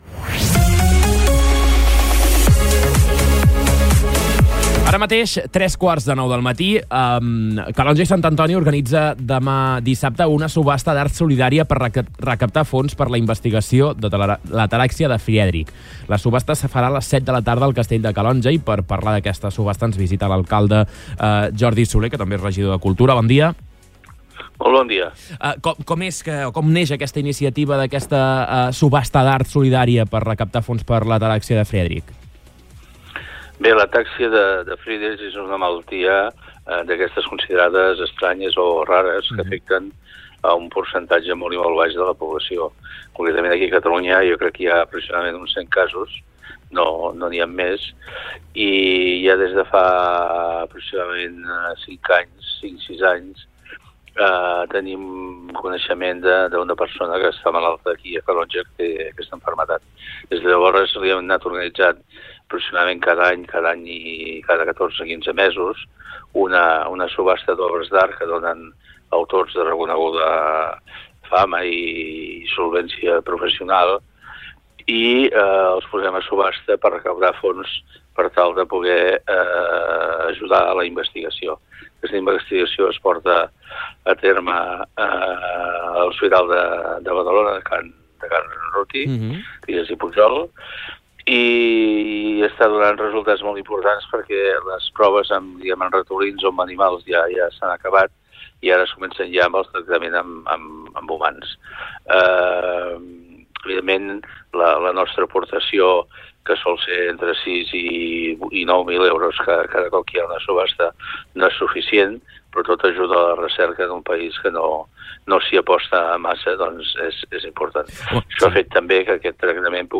Entrevistes Supermatí
I per parlar d’aquesta subhasta ens ha visitat al Supermatí l’alcalde de Calonge i Sant Antoni i regidor de cultura, Jordi Soler.